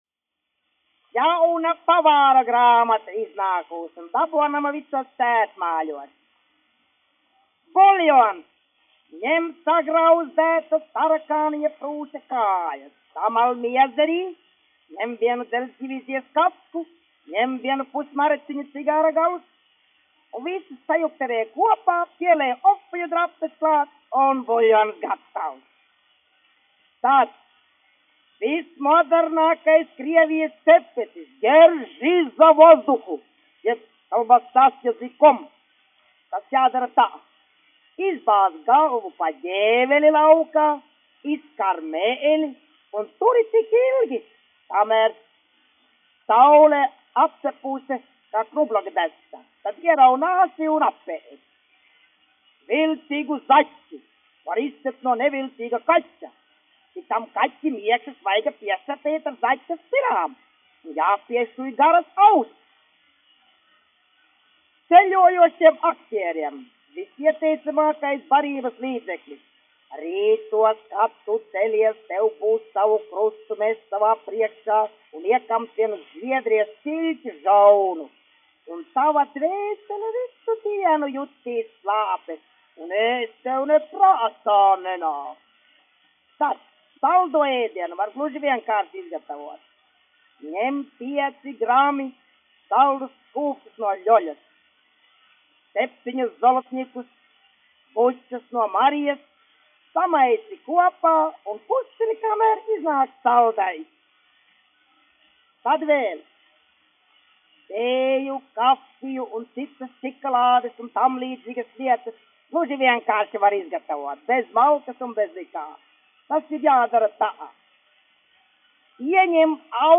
Pavāru grāmata : stāsts
1 skpl. : analogs, 78 apgr/min, mono ; 25 cm
Latvijas vēsturiskie šellaka skaņuplašu ieraksti (Kolekcija)